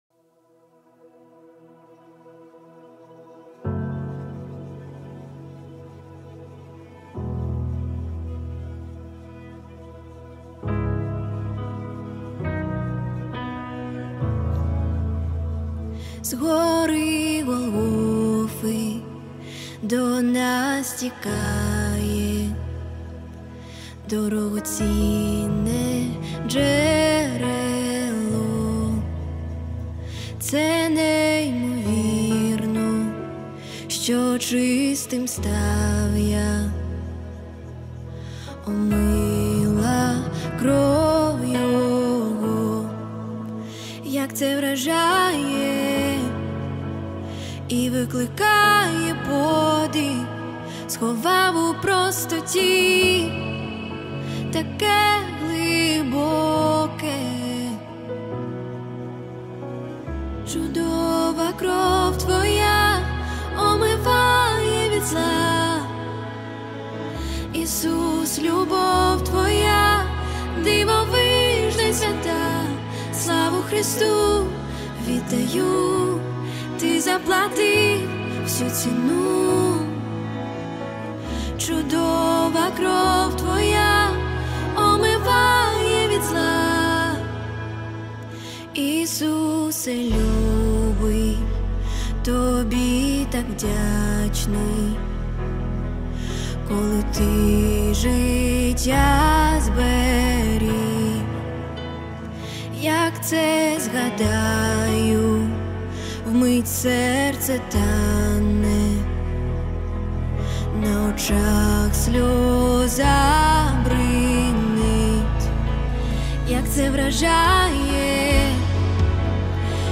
650 просмотров 366 прослушиваний 22 скачивания BPM: 68